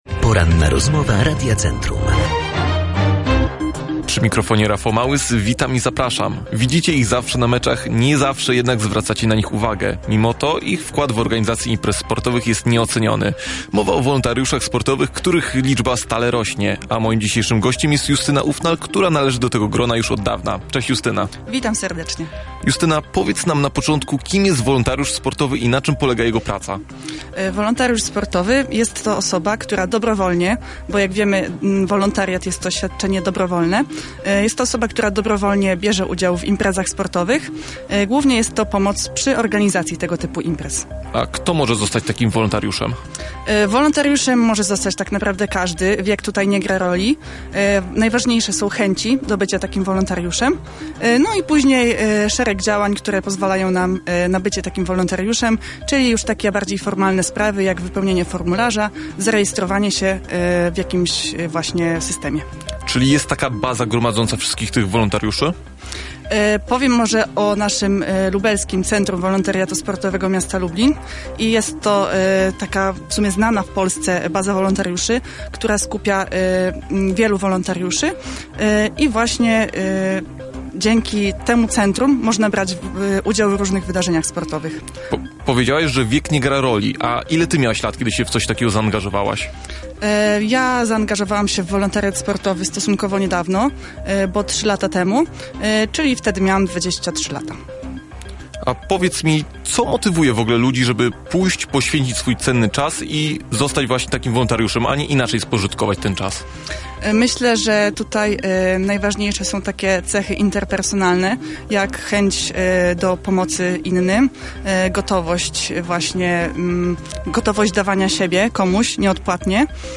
Rozmowa-po-edycji.mp3